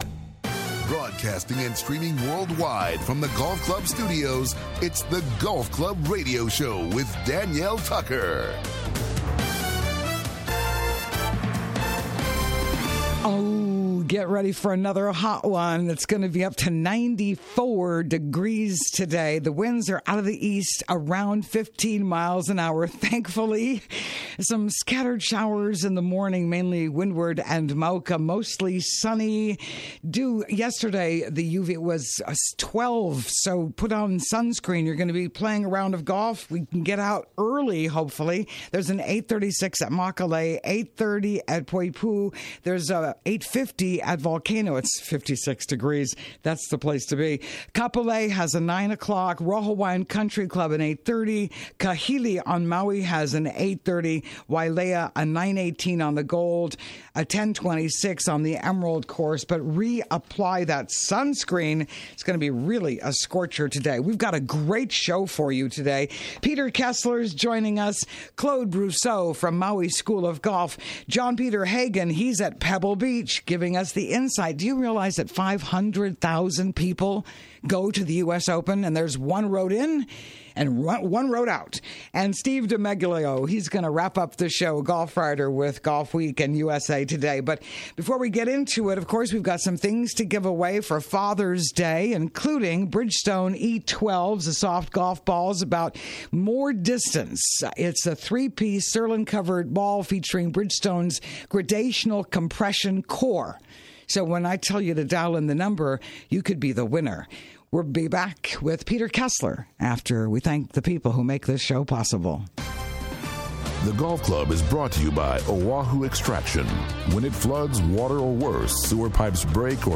Live SATURDAY MORNINGS: 7:00 AM - 8:30 AM HST